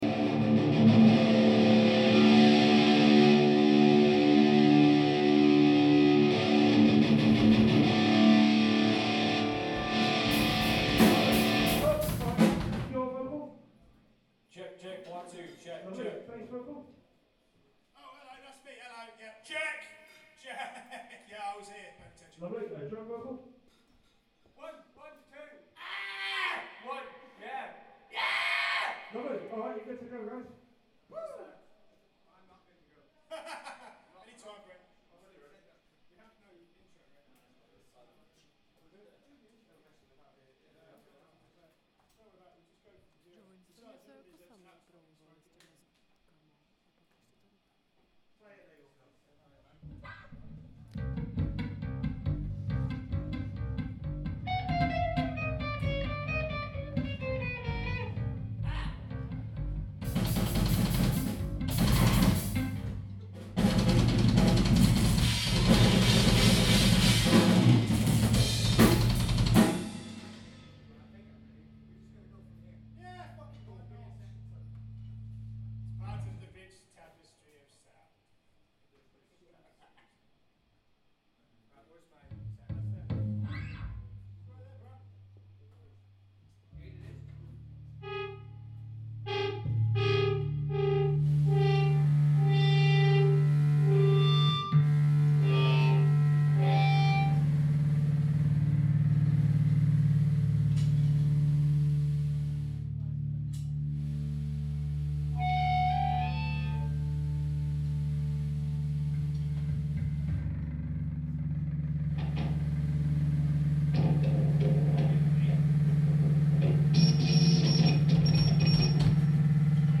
Koncert kapely